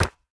resin_brick_hit5.ogg